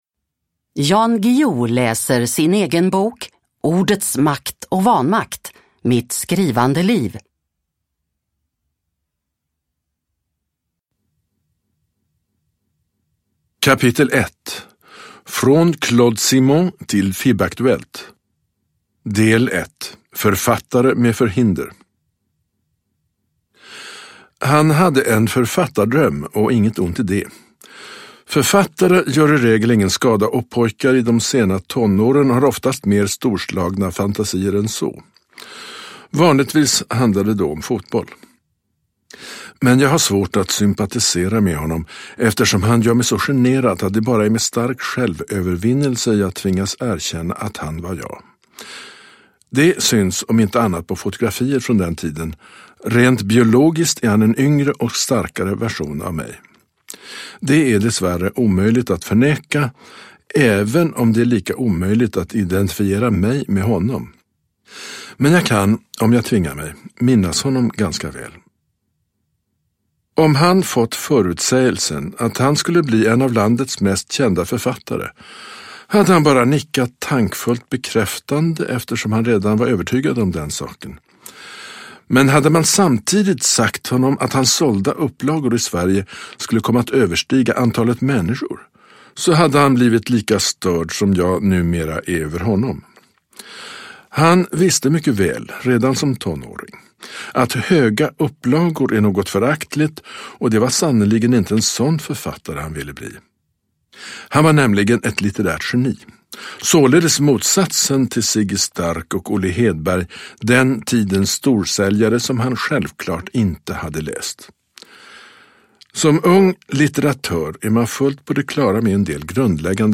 Ordets makt och vanmakt : mitt skrivande liv / Ljudbok